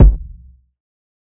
TC2 Kicks9.wav